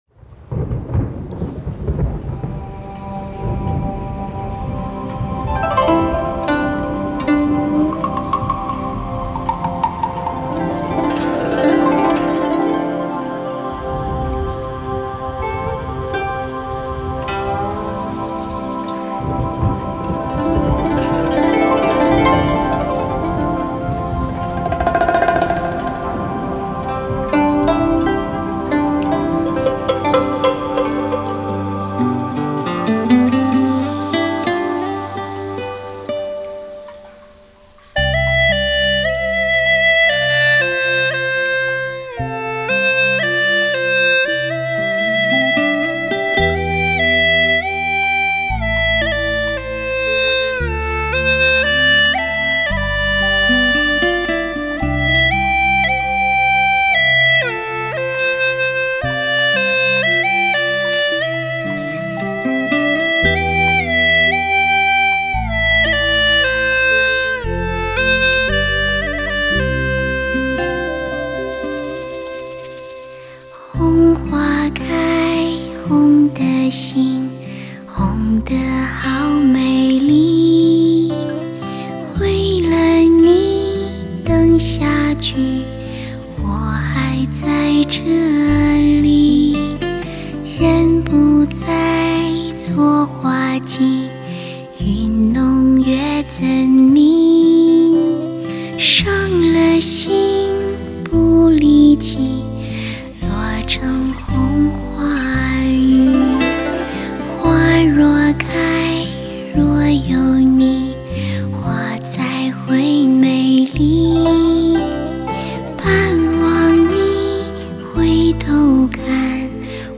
风华绝代之HIFI典范，歌者们深情的演绎完全突破语言的隔膜，
缠绵悱恻的歌声，精细无遗的录音，匠心独运的统配，质感丰富，带给你尊爵享受。